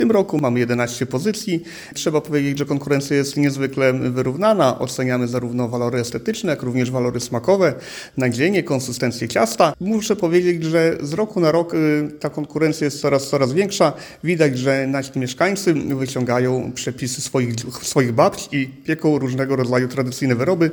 Konkurs rozstrzygnięto w Gminnym Ośrodku Kultury w miejscowości Krzywe. Pączki oceniało jury na czele z Krzysztofem Gwajem, zastępcą wójta gminy Suwałki.